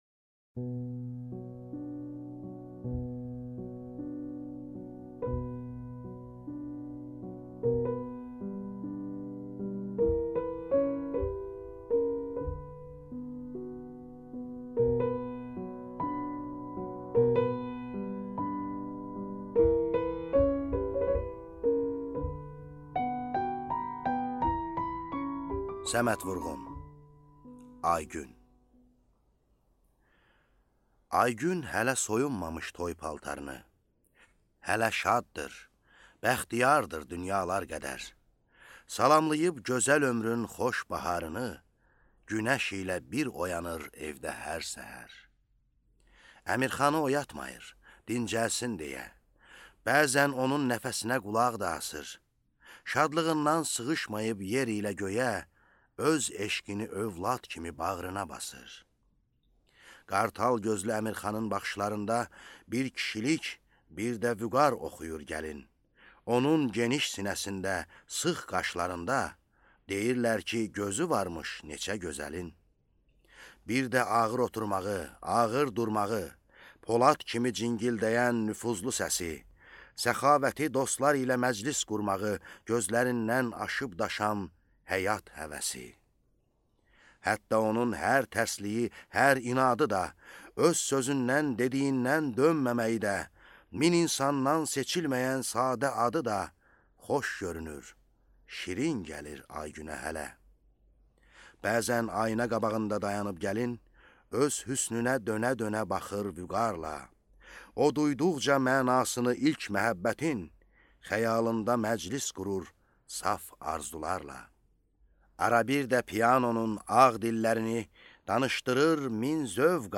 Аудиокнига Aygün | Библиотека аудиокниг